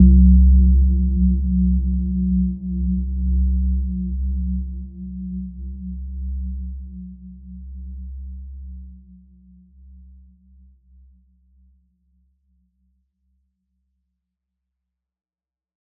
Gentle-Metallic-4-C2-f.wav